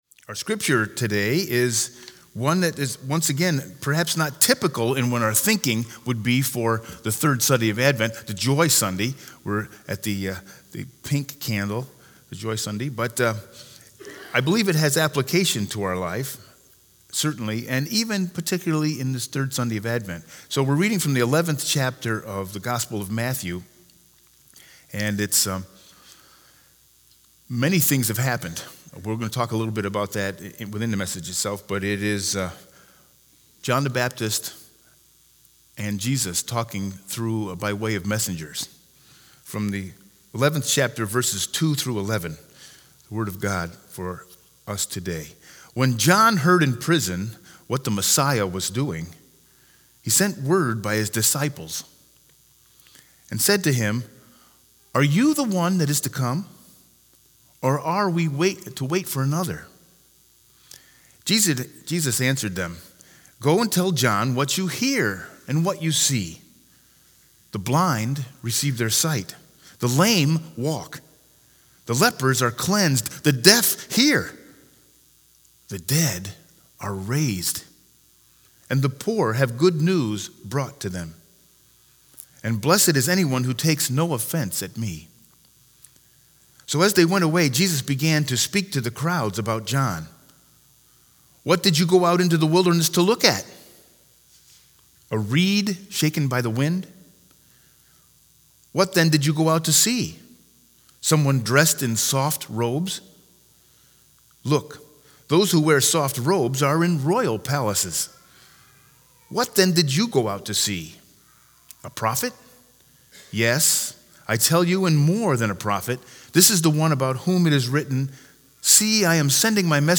Sermon 12-15-19 with Scripture Lesson Matthew 11_2-11